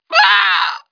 1 channel
voice_st50176.wav